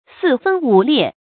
成語拼音sì fēn wǔ liè
發音讀音
四分五裂發音
成語正音分，不能讀作“fèn”。